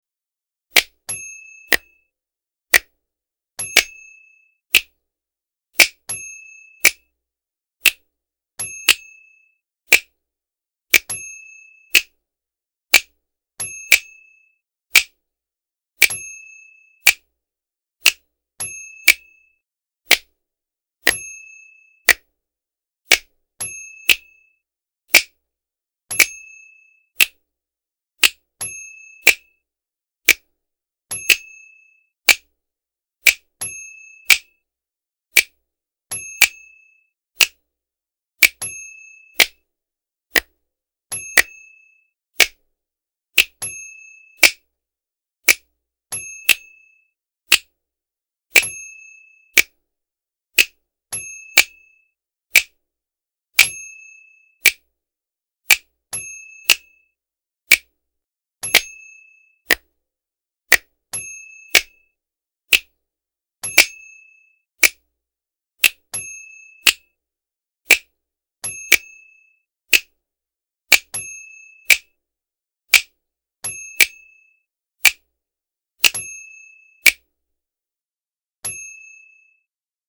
Double Finger Snaps Sound Effect
A man snapping his fingers on both hands in time for a 1:17.
DoubleFingerSnaps.mp3